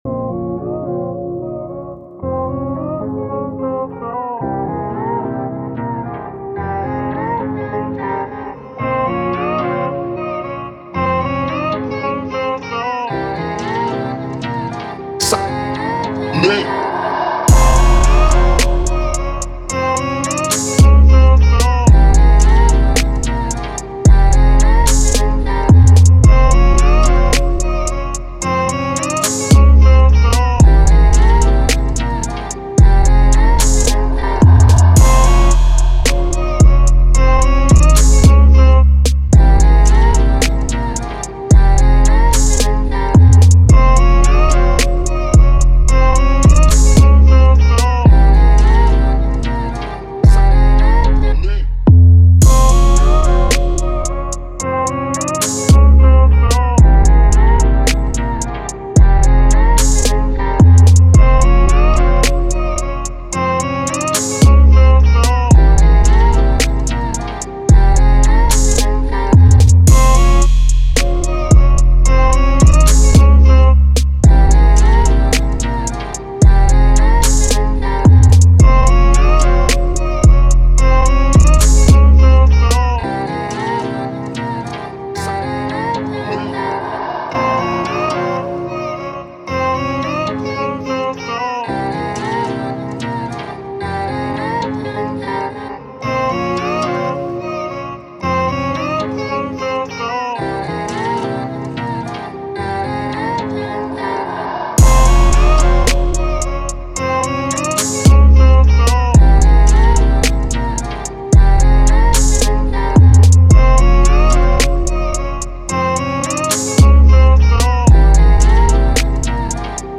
Spiritual, Calm, Emotional
Lead, Drum, Vocal